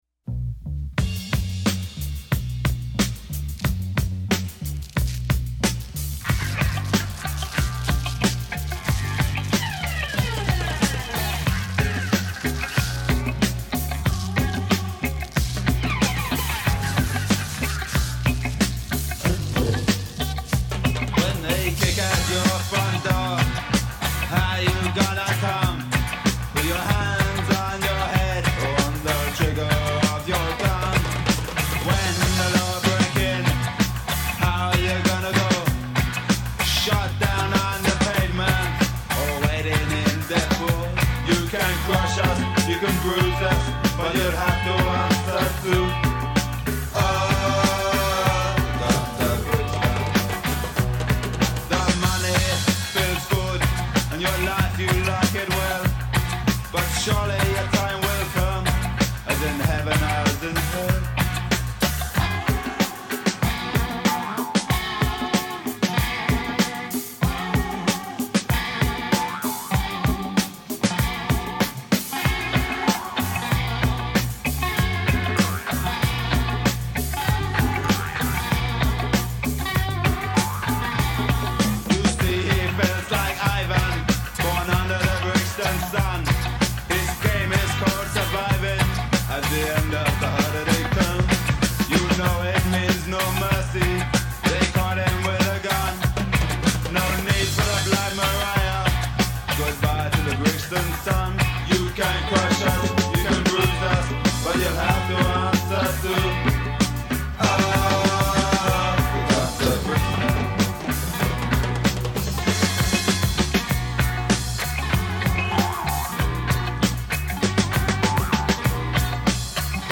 It’s a punk masterpiece that doesn’t scrimp on the melodies.